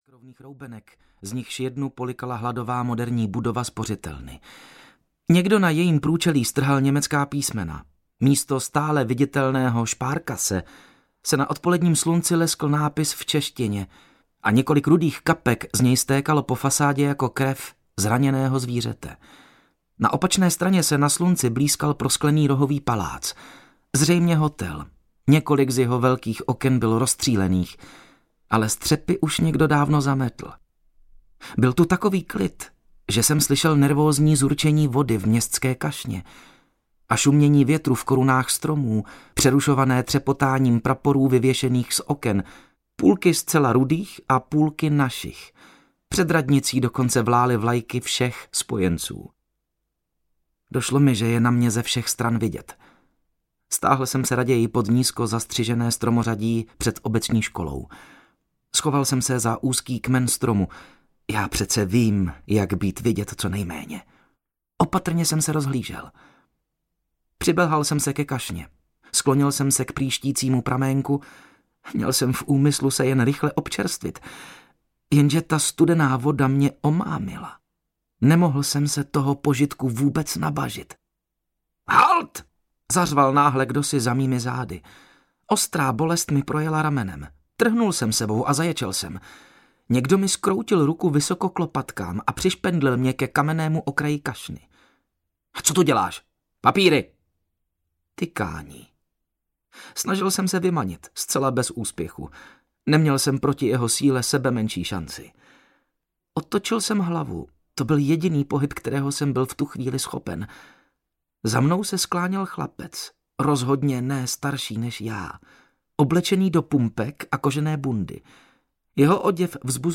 Ukázka z knihy
nepatrna-ztrata-osamelosti-audiokniha